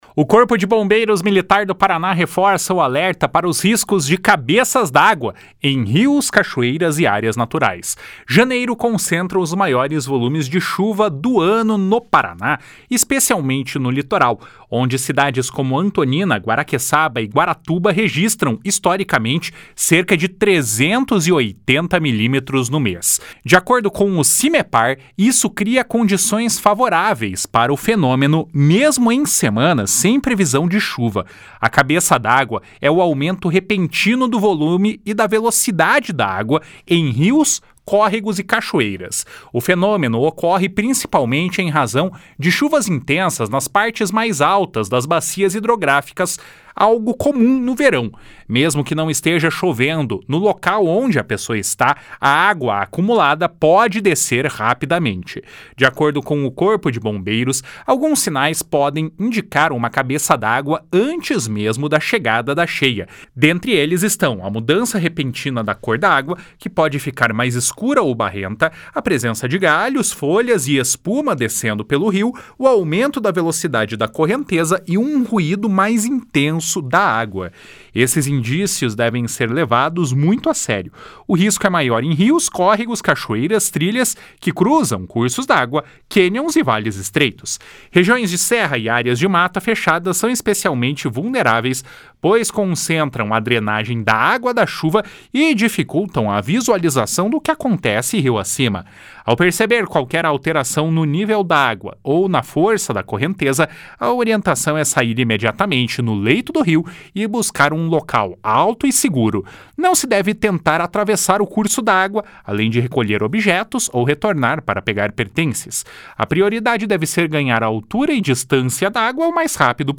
Em situações de risco ou emergência, a recomendação é manter a calma, buscar um local seguro e acionar imediatamente o Corpo de Bombeiros pelo telefone 193. (Repórter